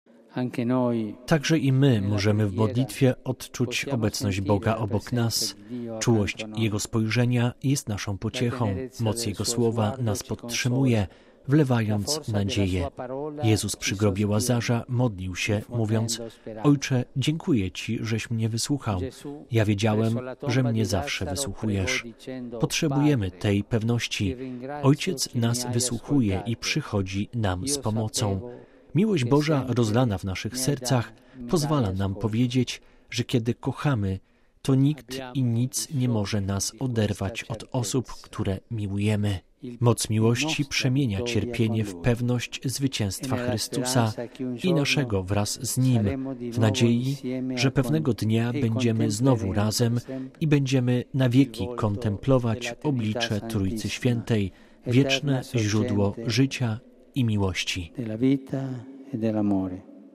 Wszyscy potrzebujemy pocieszenia, które daje Bóg. Na tym polega nasza nędza, ale i wielkość, na przyzywaniu pocieszenia od Boga, który przychodzi, by ze swą czułością otrzeć łzy na naszym obliczu – mówił Papież podczas wieczornego czuwania modlitewnego w Bazylice św. Piotra.